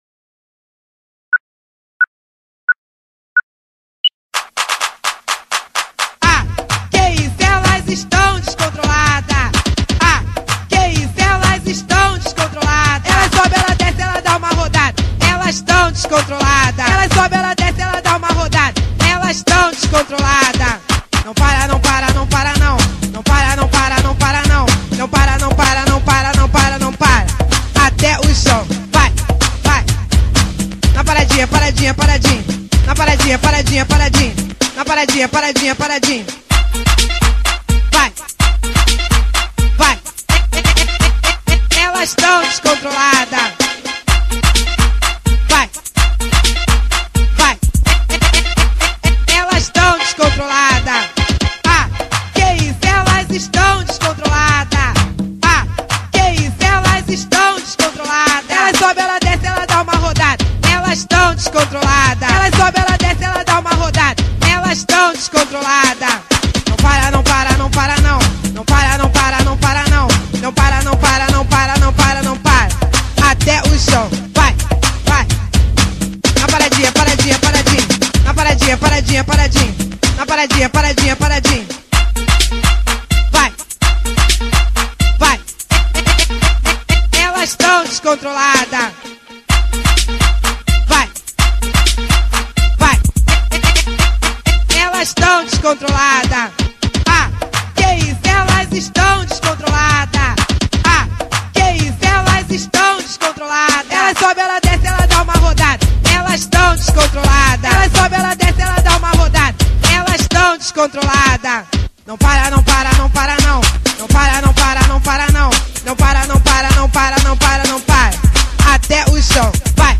Funk Para Ouvir: Clik na Musica.